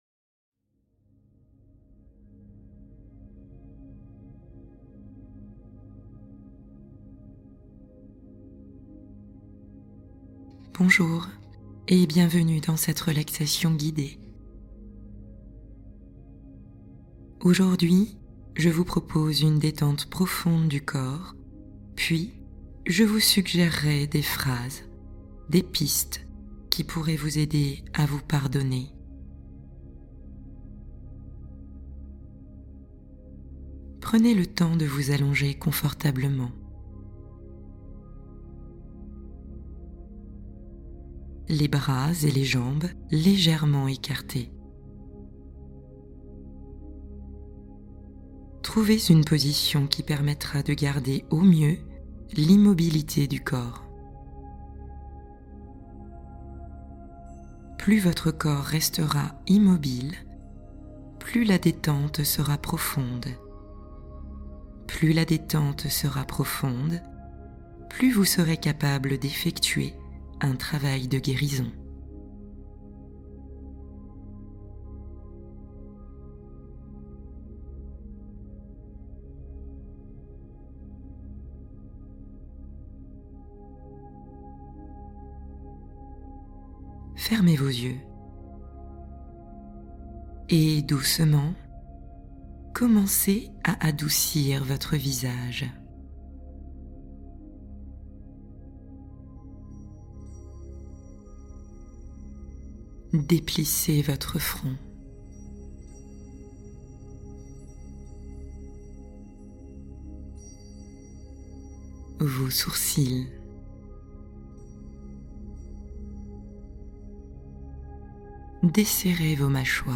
Pardonnez-vous enfin : Méditation de guérison profonde pour vous libérer du passé